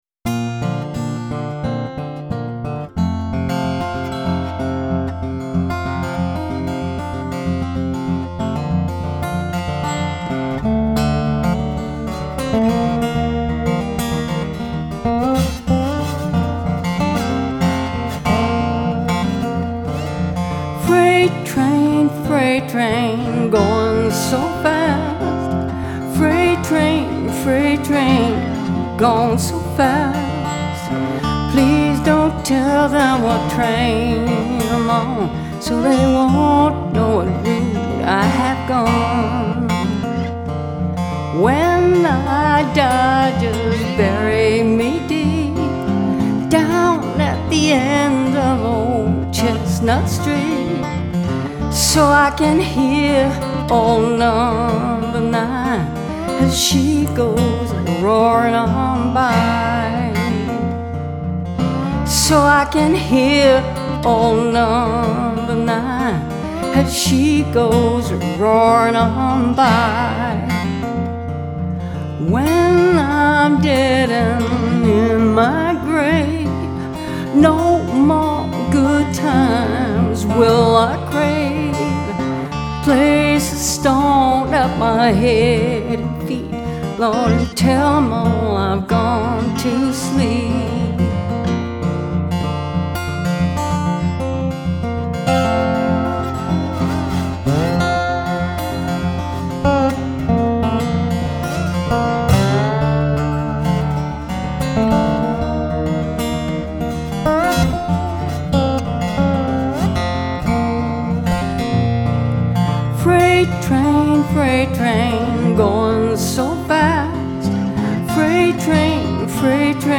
Genre : Blues